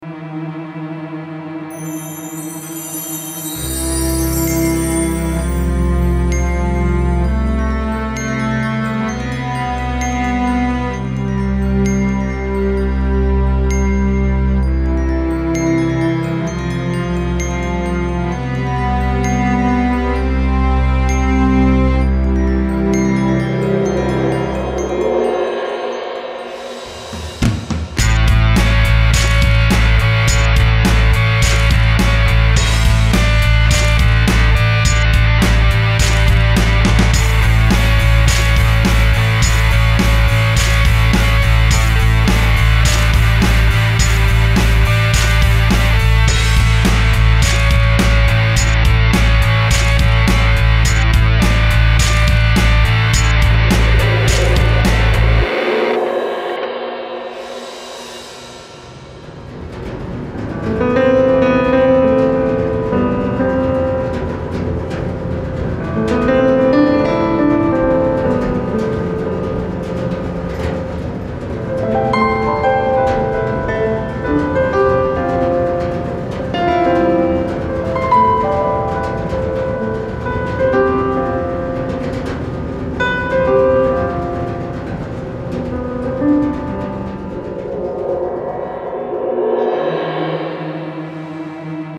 Original recording for WSDOT
Hammers clang, machinery belches, and we’re driving forward!
The waves lap up against the ferry as it’s underway and creates a natural rhythmic pattern.